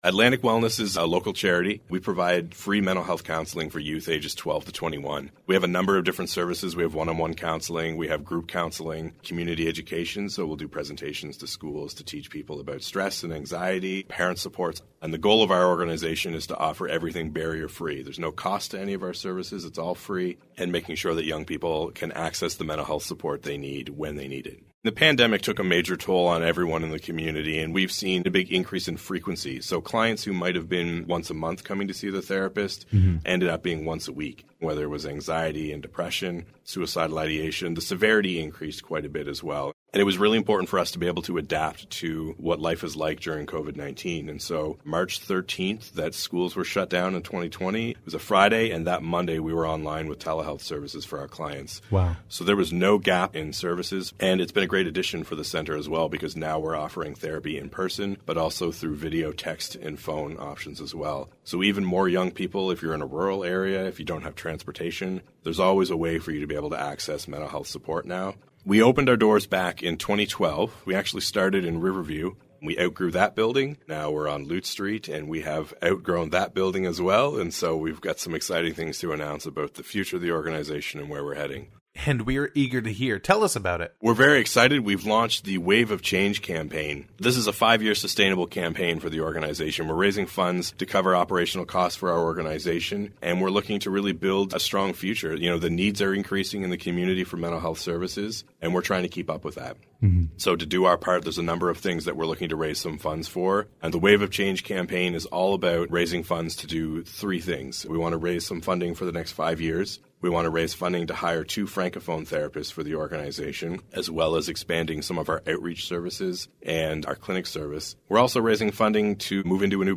INTERVIEW: Atlantic Wellness launches ‘Wave of Change’ campaign, expanding youth mental health support
INTERVIEW-AtlanticWellness-WEB-FULL.mp3